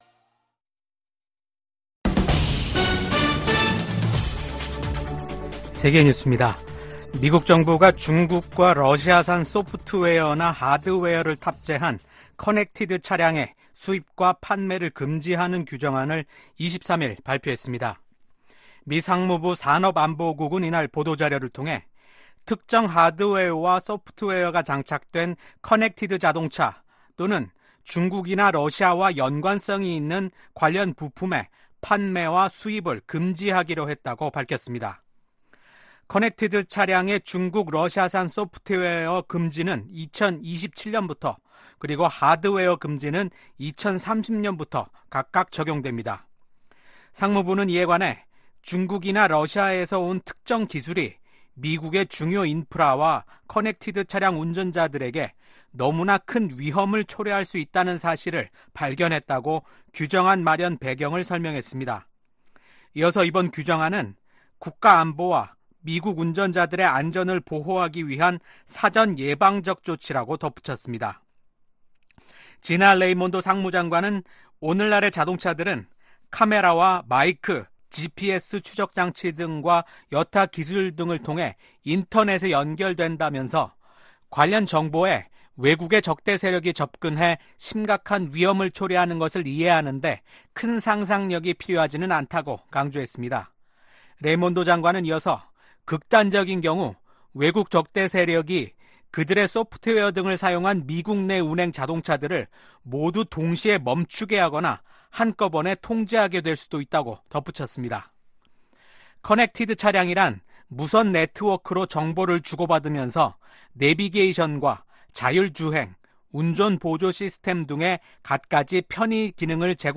VOA 한국어 아침 뉴스 프로그램 '워싱턴 뉴스 광장' 2024년 9월 21일 방송입니다. 미국과 일본, 호주, 인도 정상들이 북한의 미사일 발사와 핵무기 추구를 규탄했습니다.